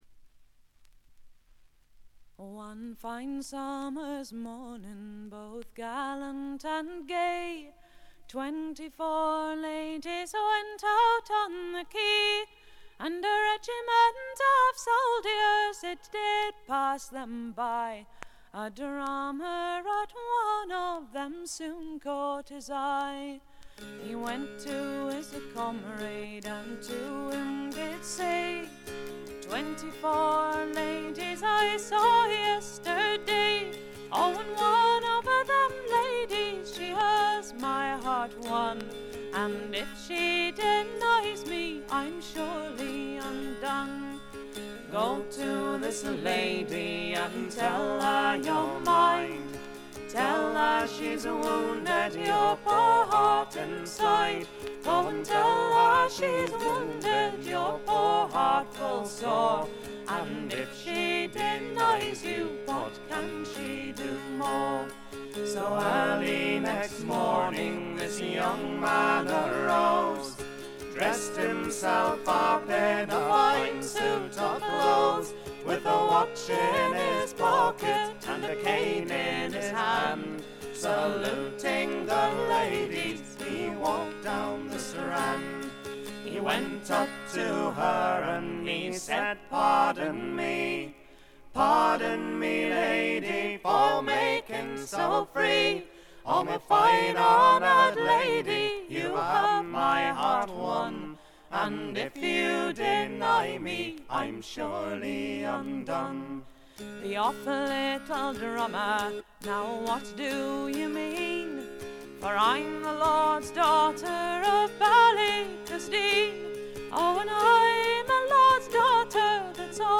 スコットランドはエディンバラの4人組トラッド・フォーク・グループ。
試聴曲は現品からの取り込み音源です。